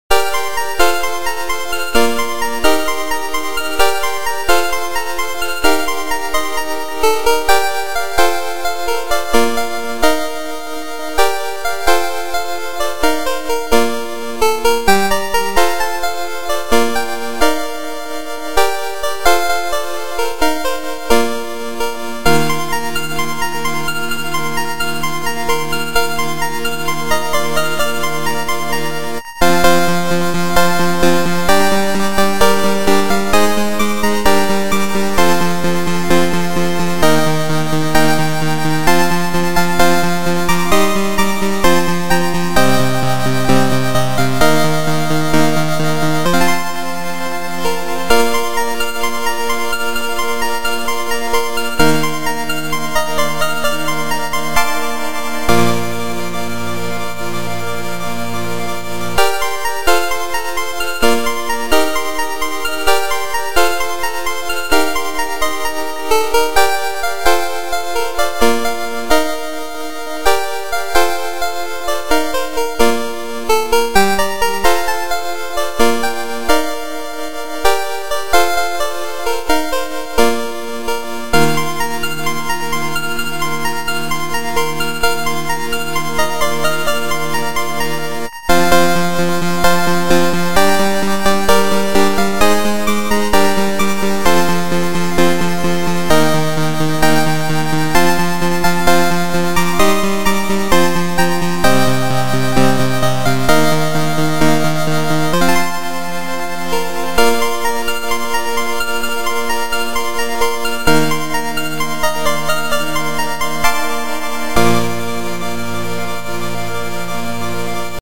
genre:chiptune
genre:remix